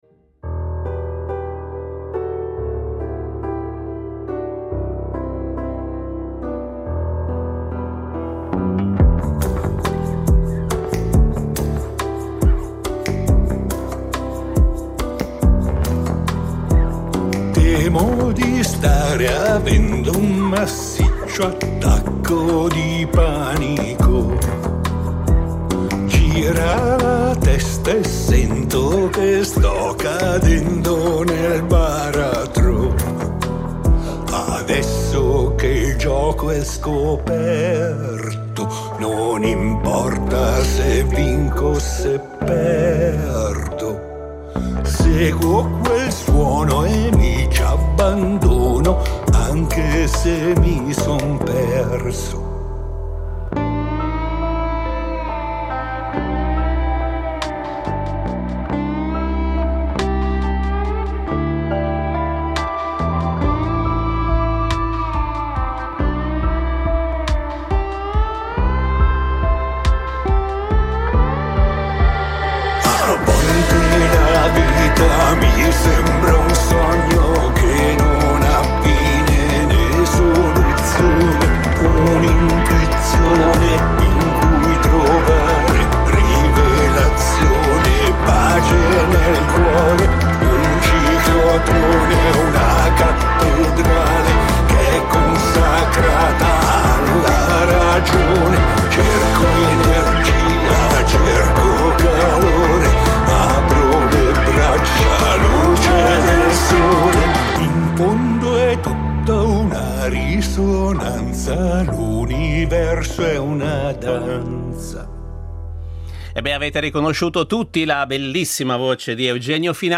Incontro con cantautore e polistrumentista italiano